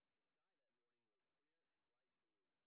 sp10_street_snr10.wav